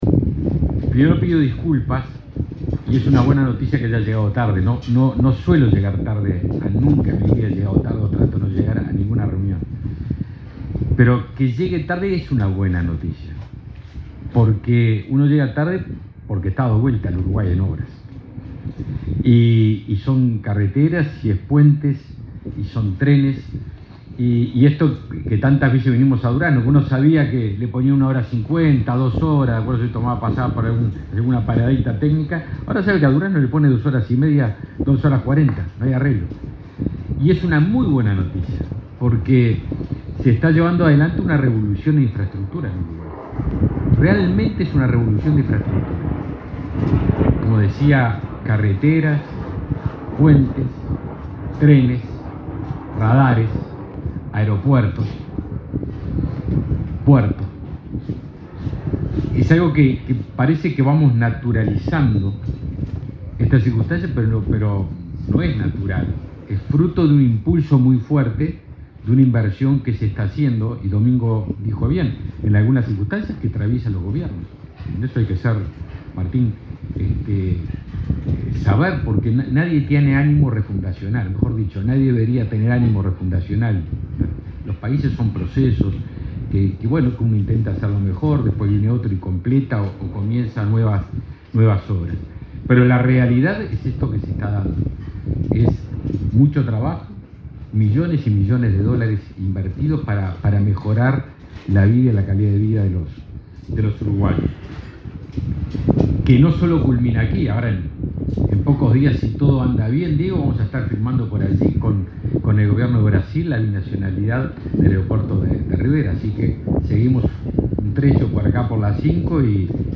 Palabras del ministro de Defensa Nacional, Javier García
El titular de la cartera, Javier García, participó en el acto.